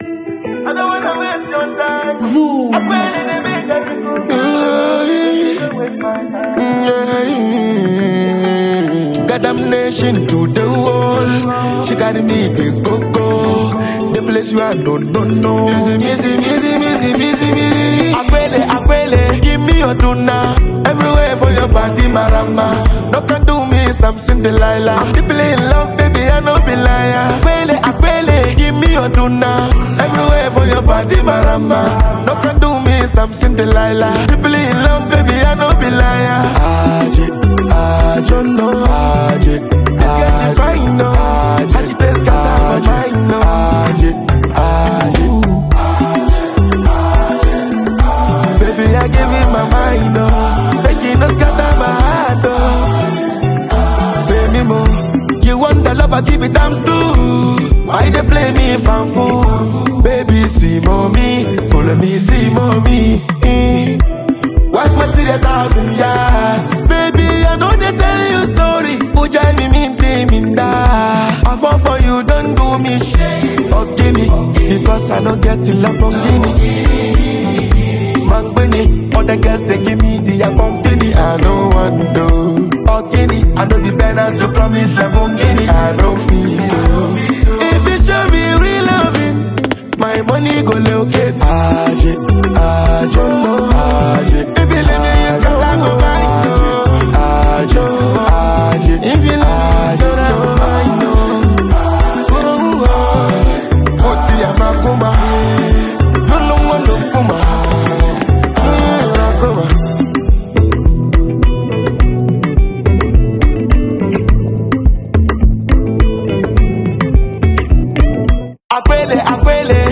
Genre: Highlife / Afrobeat